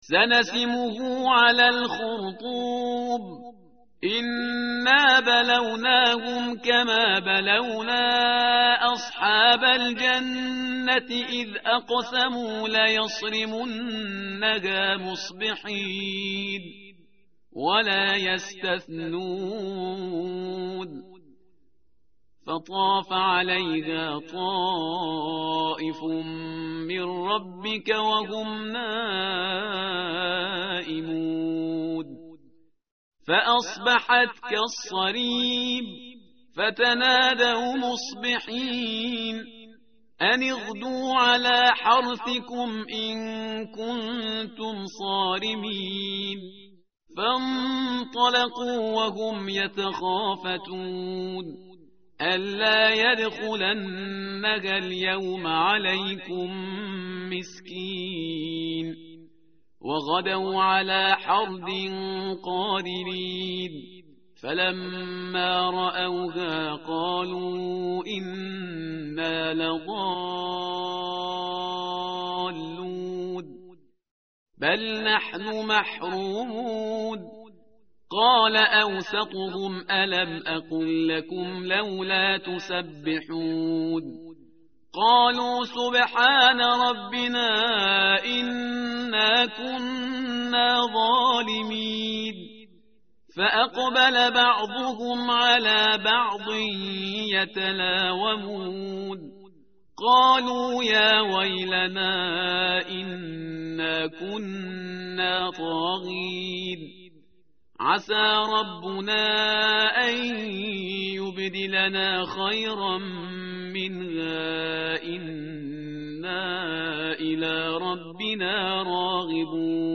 متن قرآن همراه باتلاوت قرآن و ترجمه
tartil_parhizgar_page_565.mp3